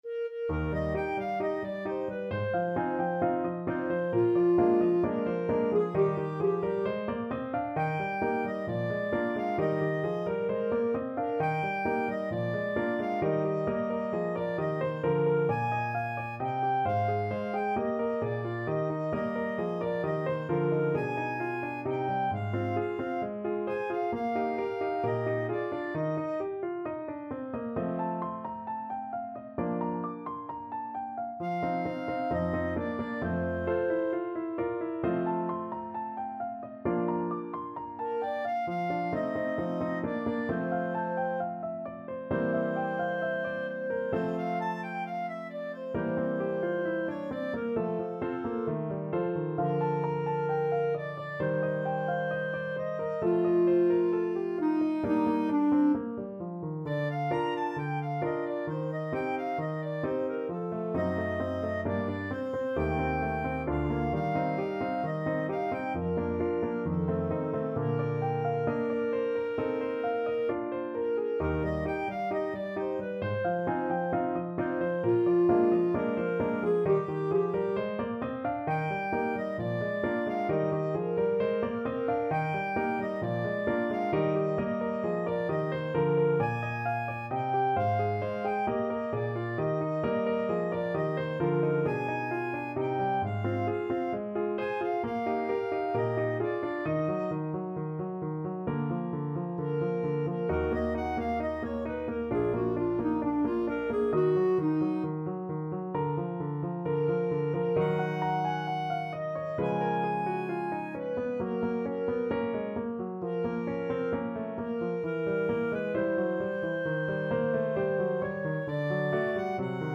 Clarinet
Eb major (Sounding Pitch) F major (Clarinet in Bb) (View more Eb major Music for Clarinet )
=132 Allegro assai (View more music marked Allegro)
2/2 (View more 2/2 Music)
Classical (View more Classical Clarinet Music)